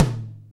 TOM KLB TO06.wav